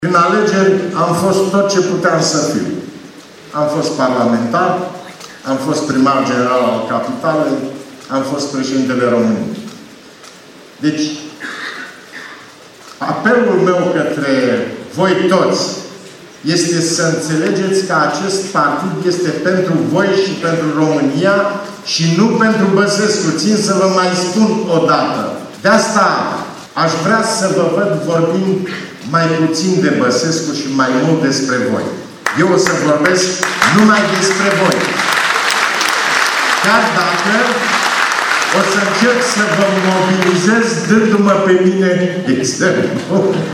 Prezent la Sala Capitol, fostul şef al statului le-a cerut membrilor PMP să vorbească mai puțin despre Traian Băsescu:
traian-basescu-pmp-.mp3